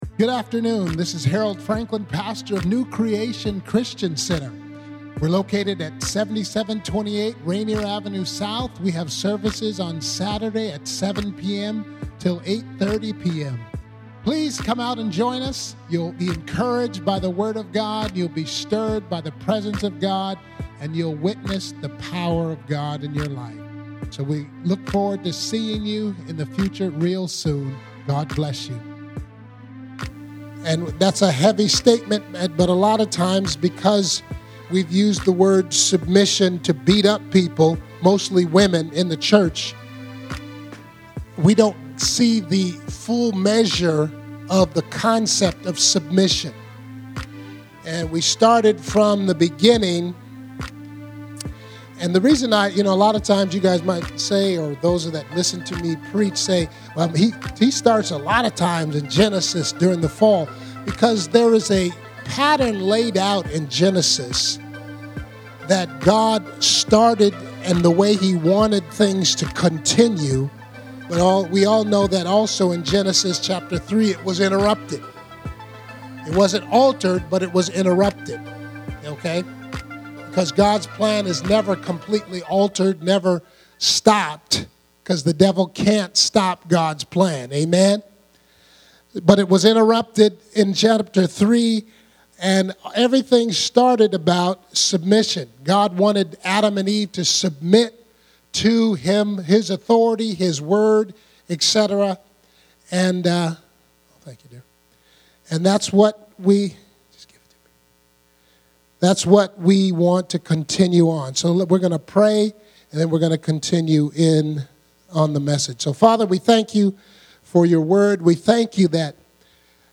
Hear the Messages we Preach, by clicking on the titles.